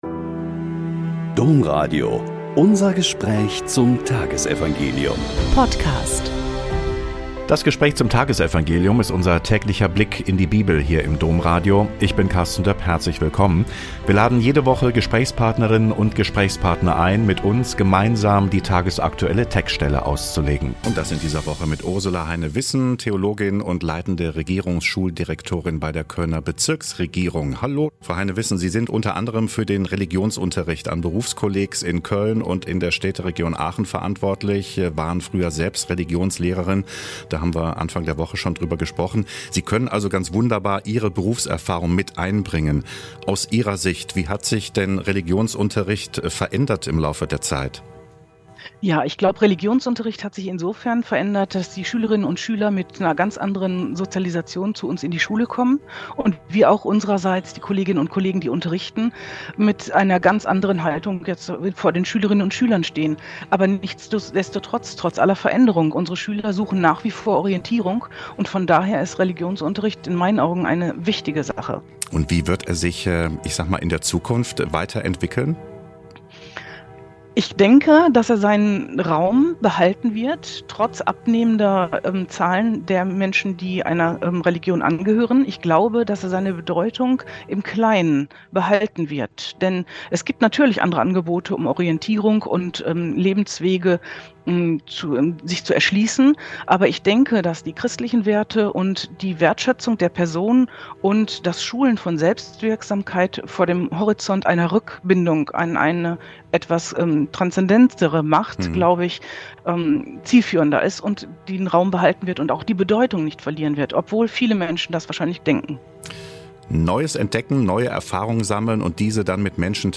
Mt 18, 1–5.10 - Gespräch